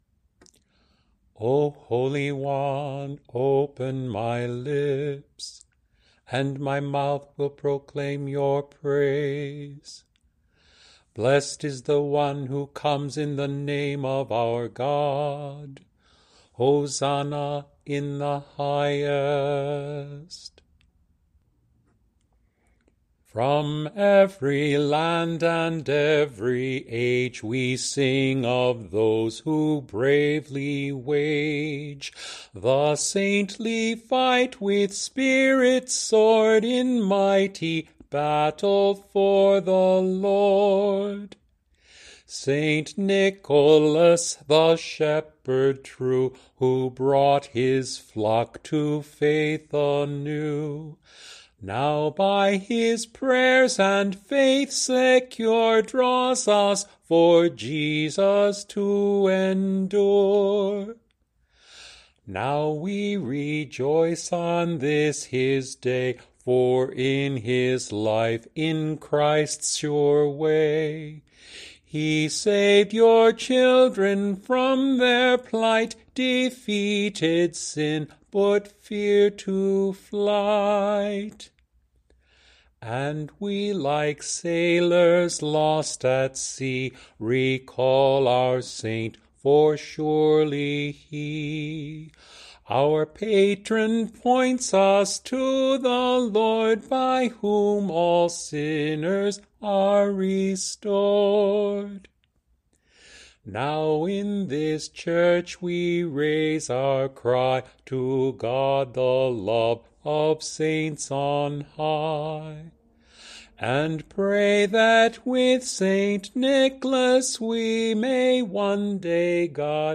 Nicholas-Lauds.mp3